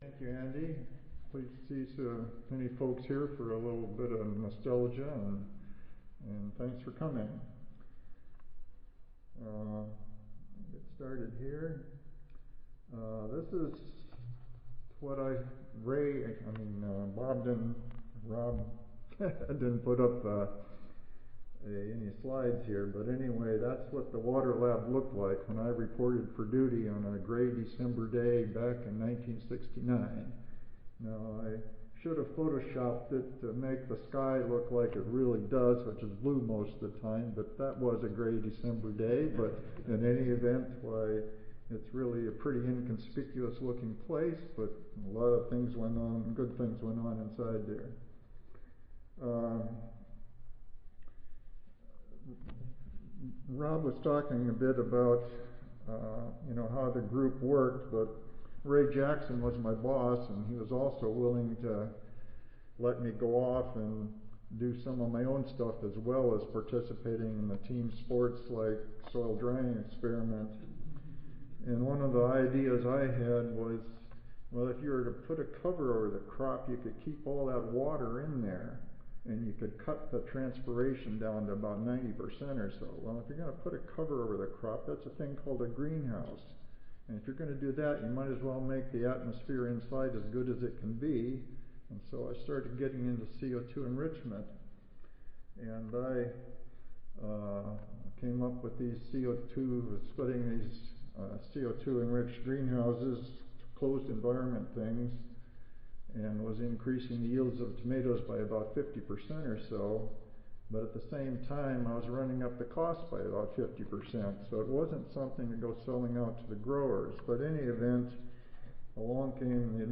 USDA ARS Audio File Recorded Presentation